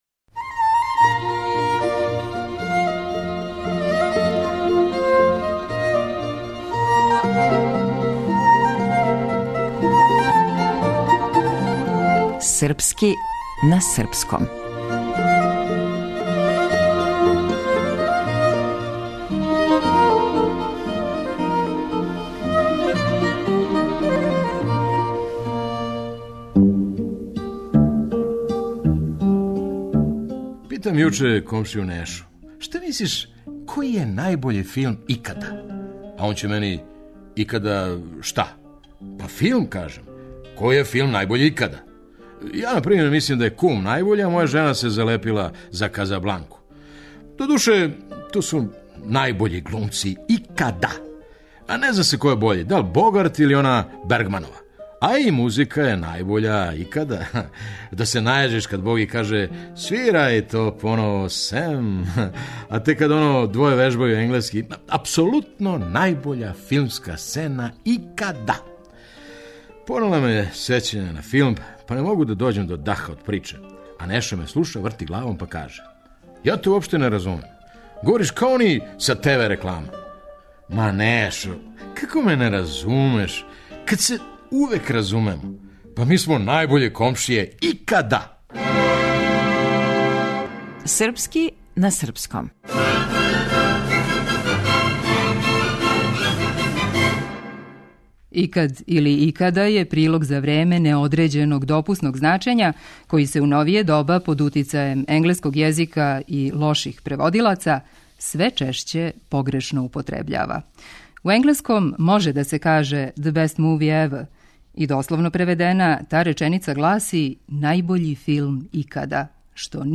Драмски уметник: Феђа Стојановић